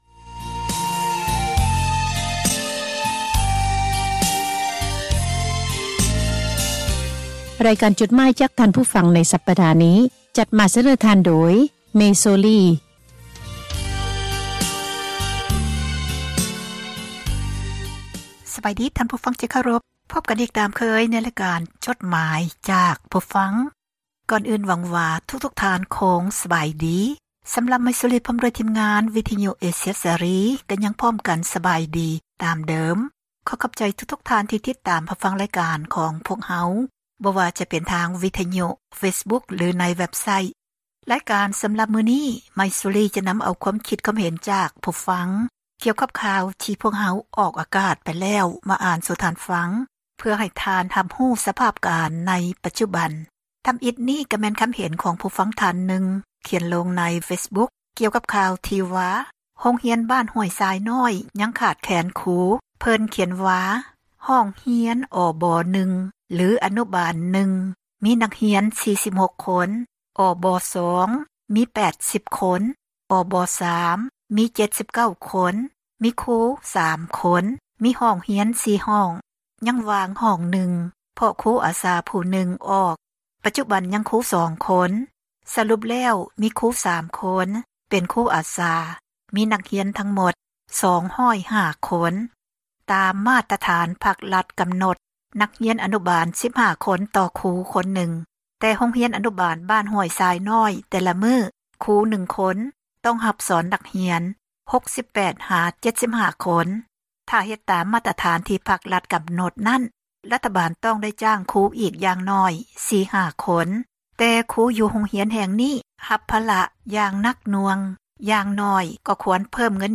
(ເຊີນທ່ານ ຟັງຣາຍລະອຽດ ຈາກສຽງບັນທຶກໄວ້) ໝາຍເຫດ: ຄວາມຄິດຄວາມເຫັນ ຂອງຜູ່ອ່ານ ທີ່ສະແດງອອກ ໃນເວັບໄຊທ໌ ແລະ ເຟສບຸກຄ໌ ຂອງວິທຍຸ ເອເຊັຽ ເສຣີ, ພວກເຮົາ ທິມງານ ວິທຍຸເອເຊັຽເສຣີ ໃຫ້ຄວາມສຳຄັນ ແລະ ຂອບໃຈ ນຳທຸກໆຖ້ອຍຄຳ , ແລະ ມີໜ້າທີ່ ນຳມາອ່ານໃຫ້ທ່ານ ໄດ້ຮັບຟັງກັນ ແລະ ບໍ່ໄດ້ເສກສັນປັ້ນແຕ່ງໃດໆ , ມີພຽງແຕ່ ປ່ຽນຄຳສັພ ທີ່ບໍ່ສຸພາບ ໃຫ້ເບົາລົງ ເທົ່ານັ້ນ. ດັ່ງນັ້ນ ຂໍໃຫ້ທ່ານຜູ່ຟັງ ຈົ່ງຕັດສິນໃຈເອົາເອງ ວ່າ ຄວາມຄິດເຫັນນັ້ນ ເປັນໜ້າເຊື່ອຖື ແລະ ຄວາມຈິງ ຫລາຍ-ໜ້ອຍ ປານໃດ.